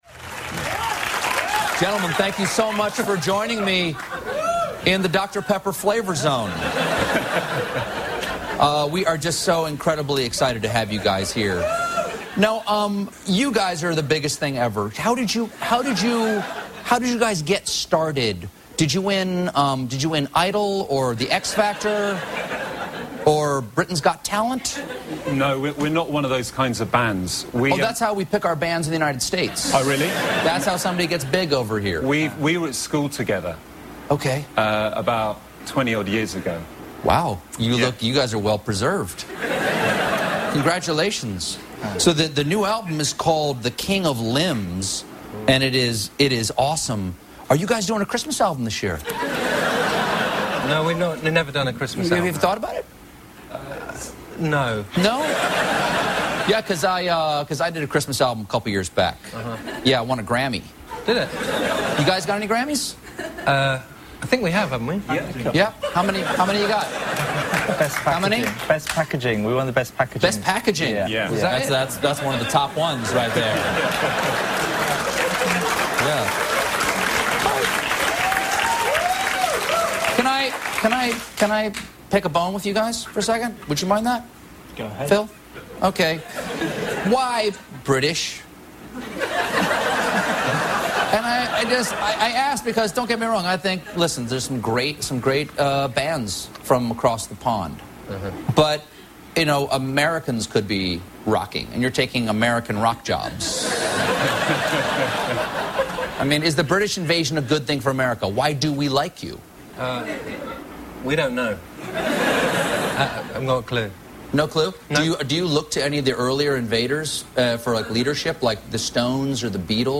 Radiohead interviewed by Colbert